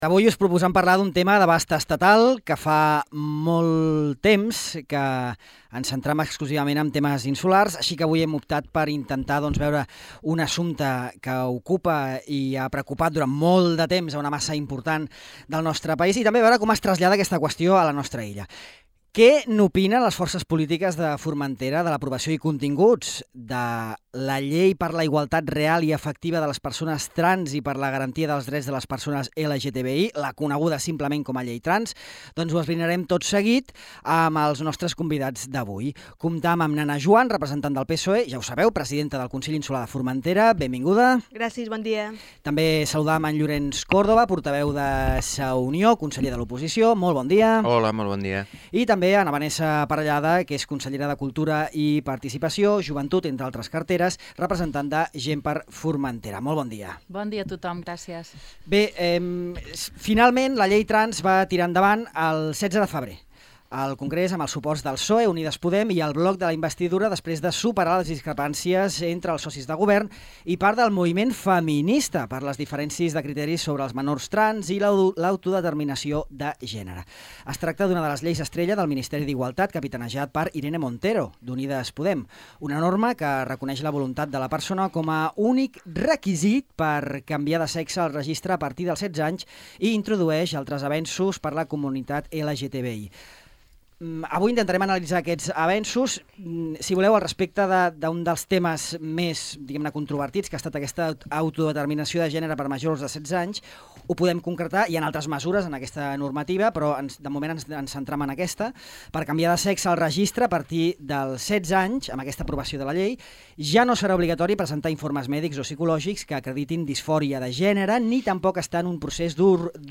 La Llei Trans, a la tertúlia política
Ana Juan, presidenta del Consell (PSOE); Llorenç Córdoba, portaveu de l’oposició (Sa Unió); i Vanessa Parellada, consellera de Cultura, Participació Ciutadana i Joventut (GxF); han debatut en una nova edició de la Tribuna Oberta sobre la Llei per a la igualtat real i efectiva de les persones trans i per a la garantia dels drets de les persones LGTBI, coneguda com a Llei Trans.